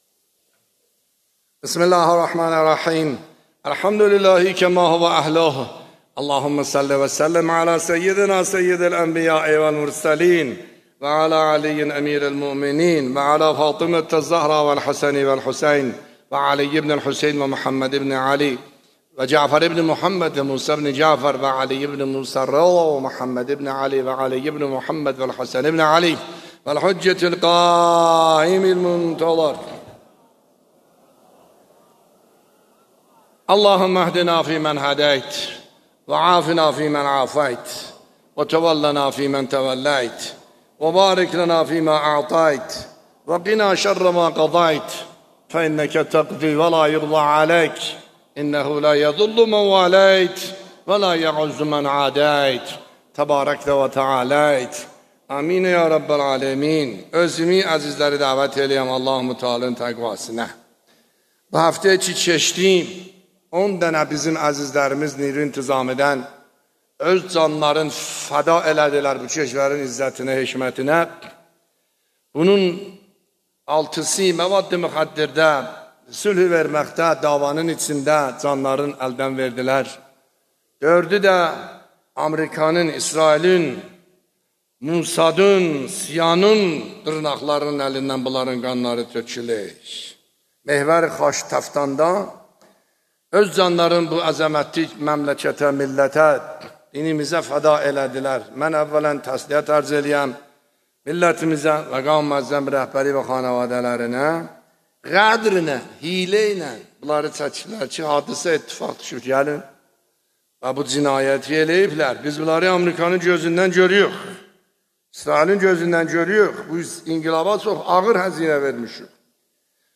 خطبه‌ های نماز جمعه اردبیل | آیت الله عاملی (06 مرداد 1402) + متن
بیانات آیت الله سید حسن عاملی نماینده ولی فقیه و امام جمعه اردبیل در خطبه های نماز جمعه در 06 مرداد 1402